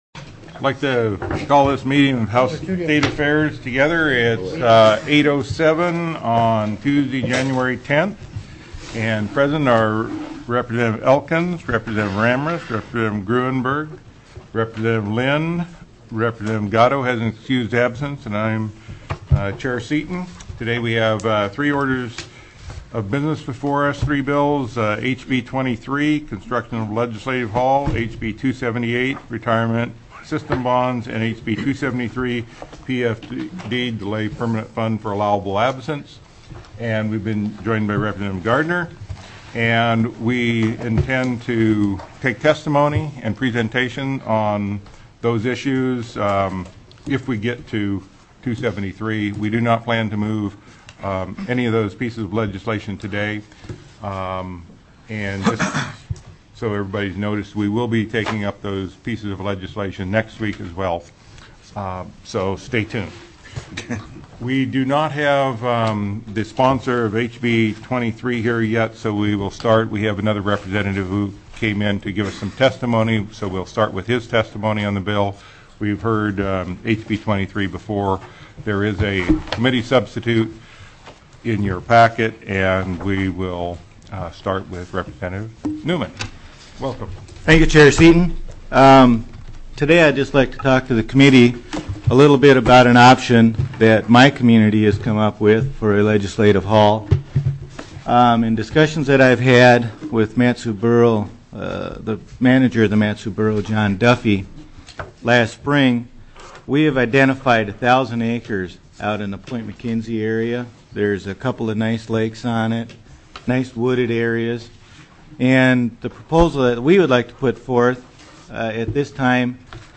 01/12/2006 08:00 AM House STATE AFFAIRS